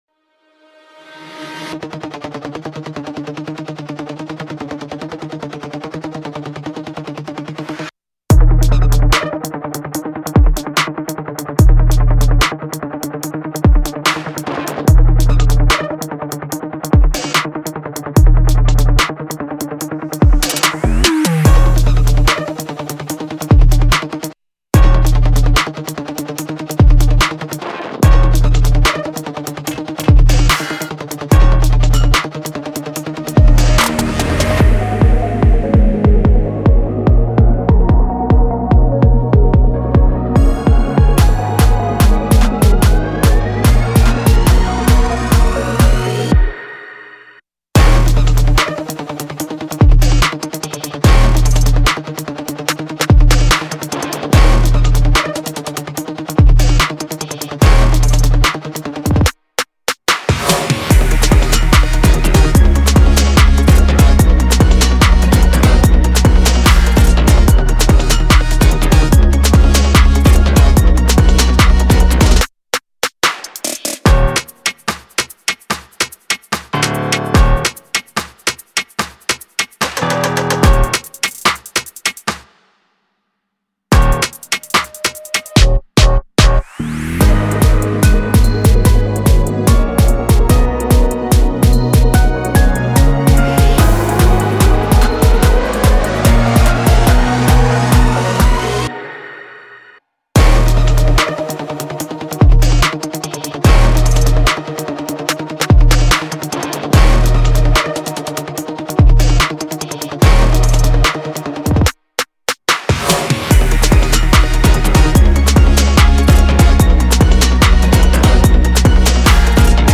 2025 in K-Pop Instrumentals